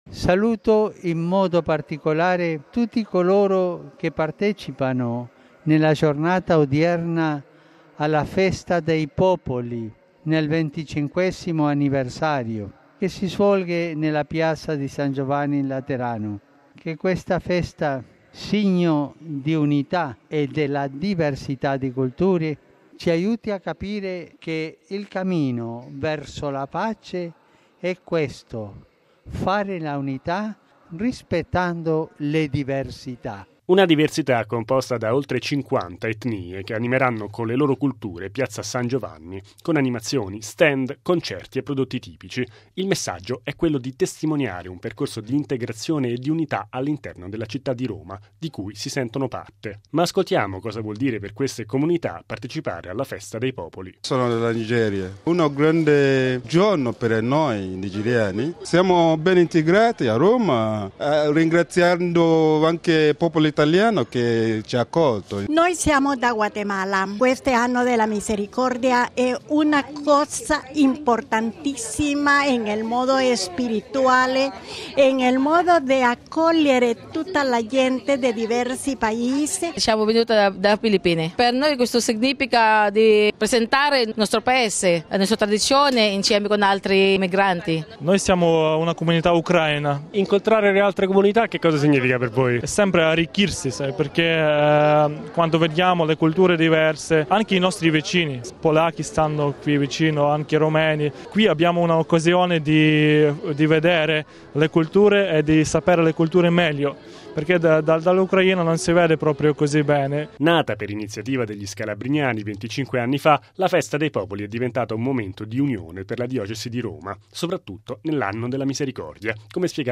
La pace è possibile rispettando le diversità. Con questo augurio al Regina Caeli di Piazza San Pietro Papa Francesco ha voluto raggiungere la “Festa dei popoli” che si svolge oggi a Piazza San Giovanni  in Laterano.